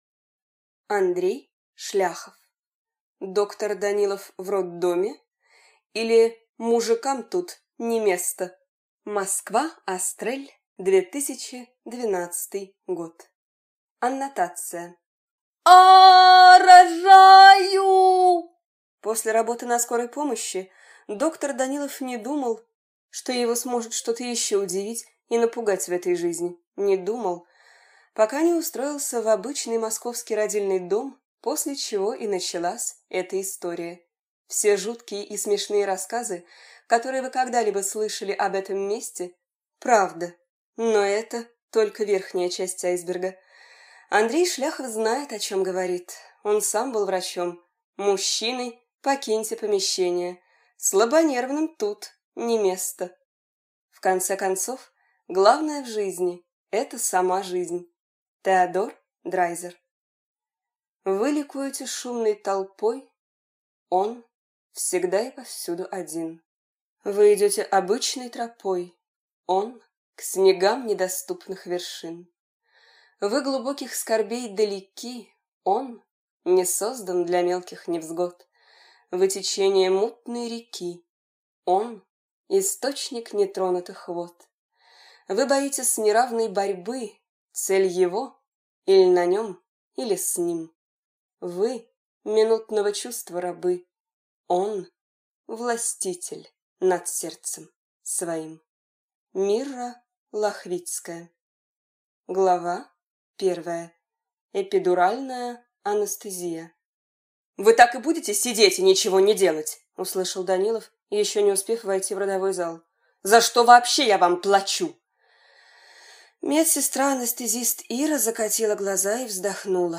Аудиокнига Доктор Данилов в роддоме, или Мужикам тут не место - купить, скачать и слушать онлайн | КнигоПоиск